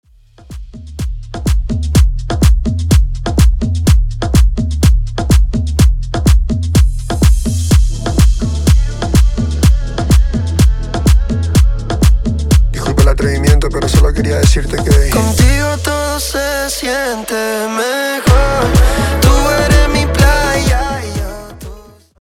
Coro Dirty